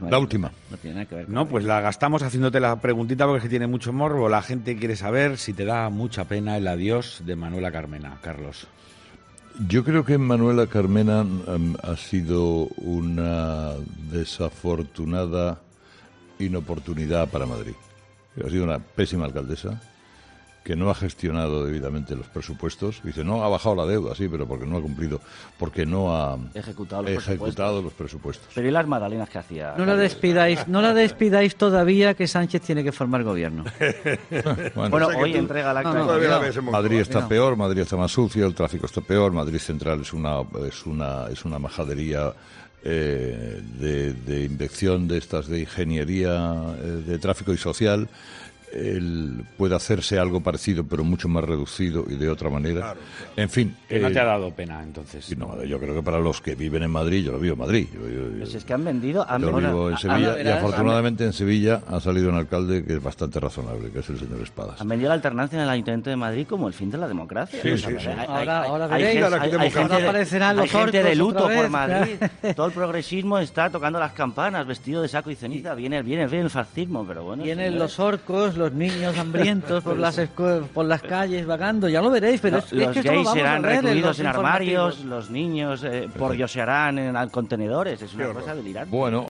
La tertulia de los oyentes
Los tertulianos del programa han coincidido con la opinión de Herrera.